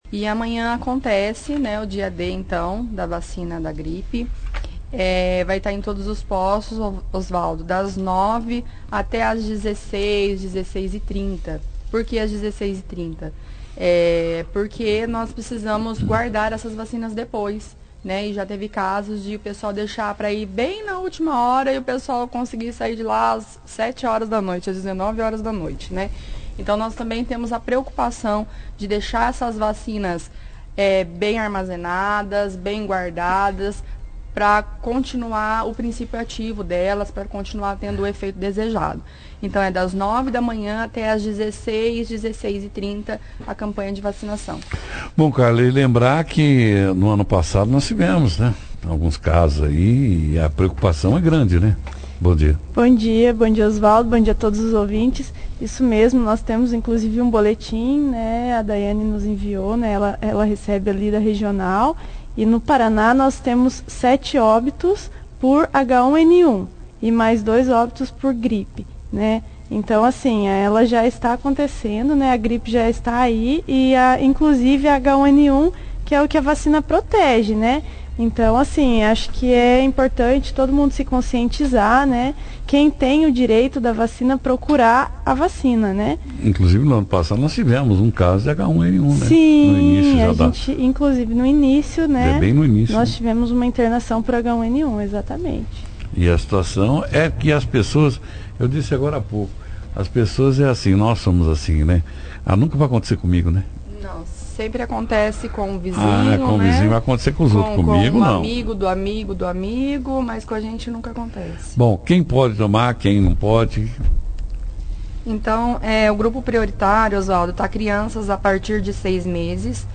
participaram a 1ª do jornal Operação Cidade desta sexta-feira, 03/05/19, falando sobre a vacinação contra a gripe neste sábado, 04/05, quando acontece o chamado “Dia D” da Campanha nacional de vacinação contra o vírus influenza, que provoca a gripe, quem pode tomar a vacina e quais os postos que estarão atendendo.